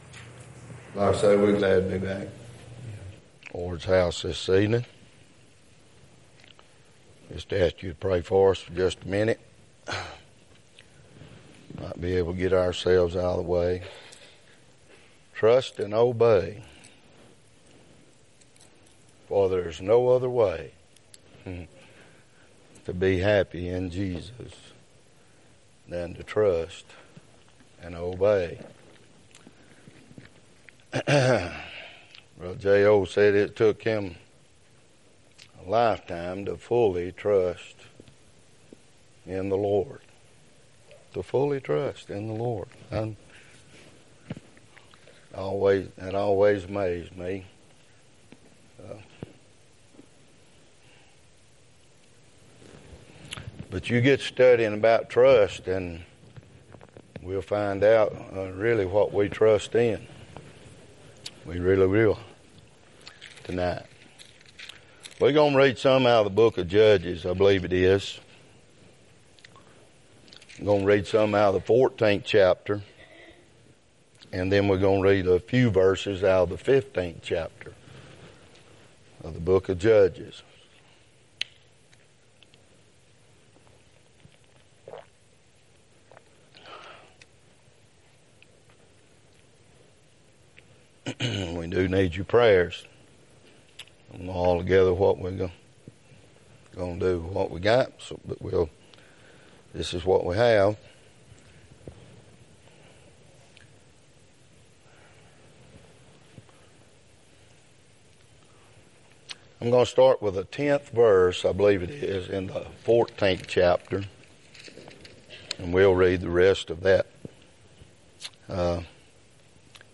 Wednesday night – Page 11 – Mountain View Baptist Church